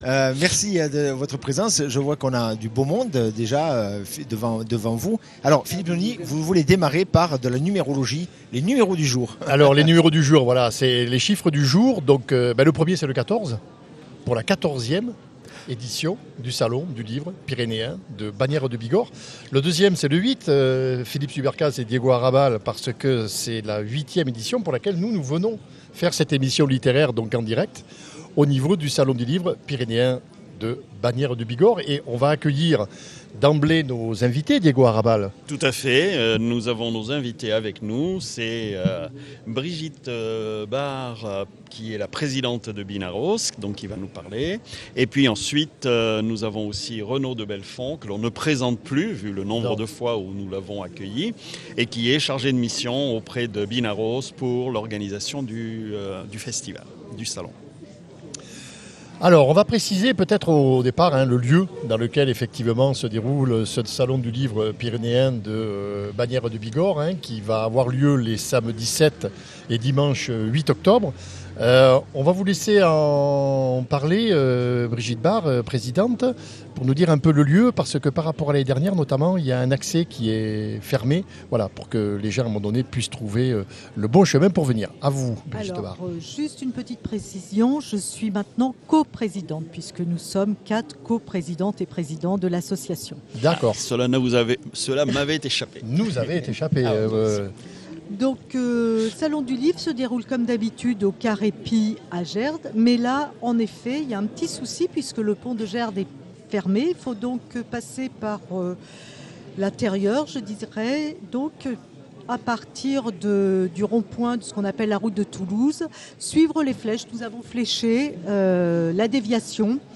Direct Salon du livre Pyrénéens